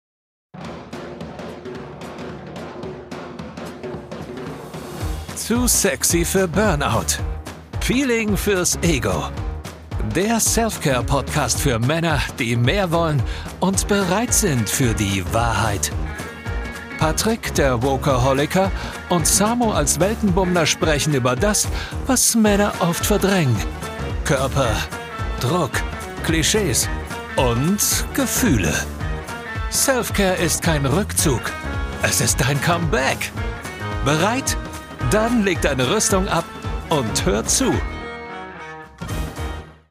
Zwei Männer mit Mikro und der radikalen Lust auf echt Gespräche